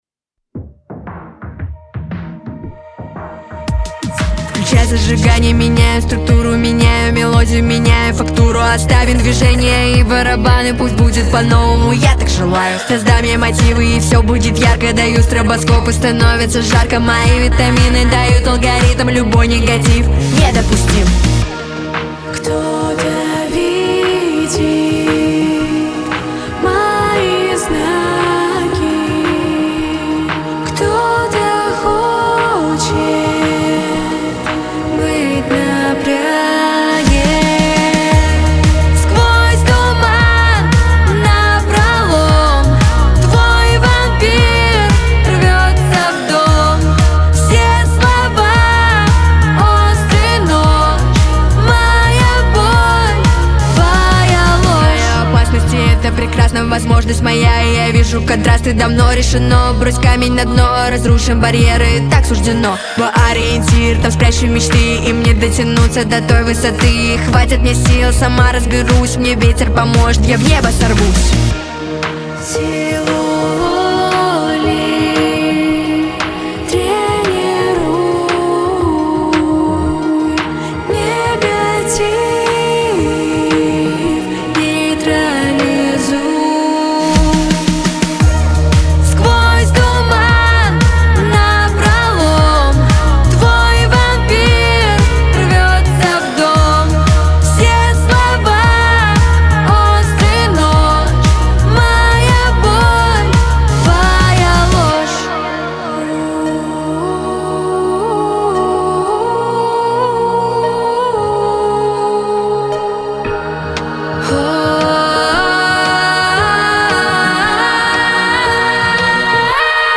• Категория: Детские песни
подростковые песни